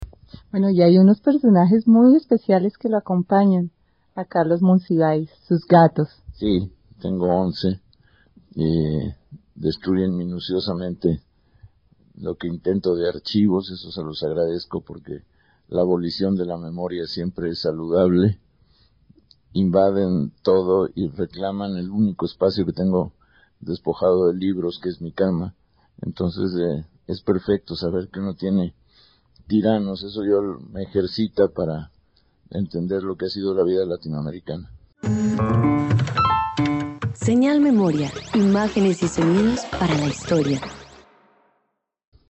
En una entrevista concedida a la Emisora HJCK, Monsiváis habla de los once gatos que poseía en aquel 2001. Hizo una curiosa reflexión sobre este papel.